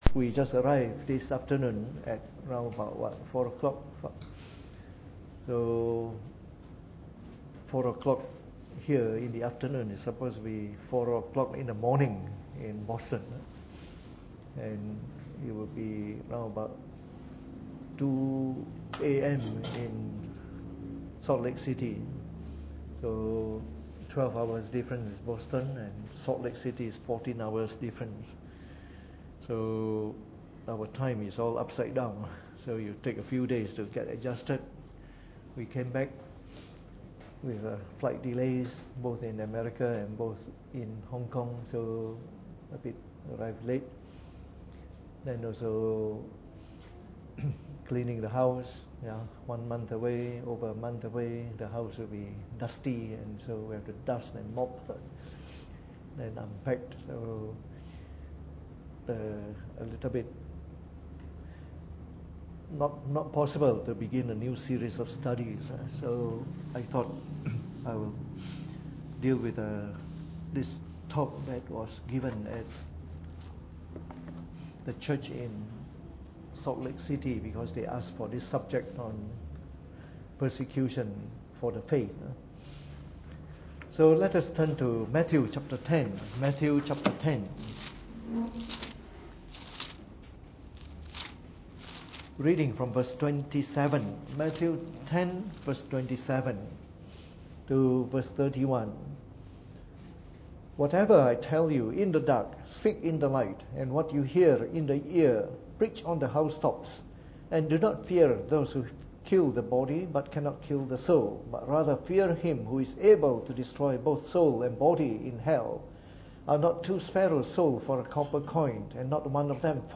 Preached on the 25th of October 2017 during the Bible Study.